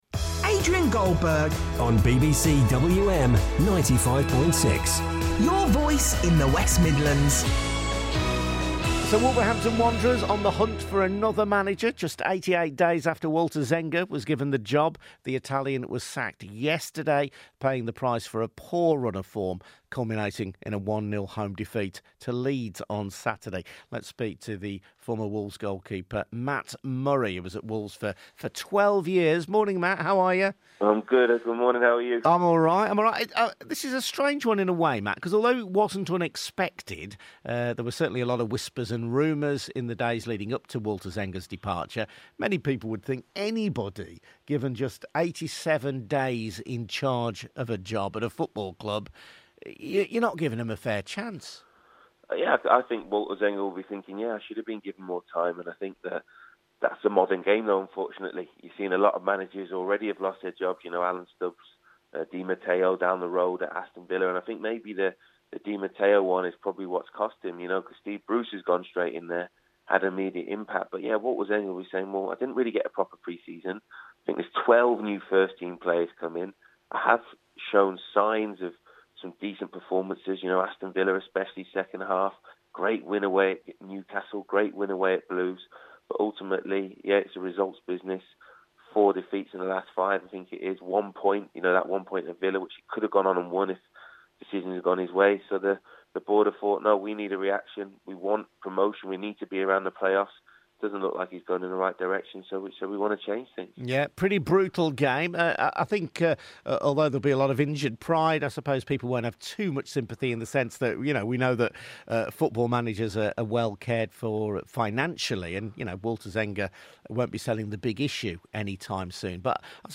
on the BBC WM Breakfast show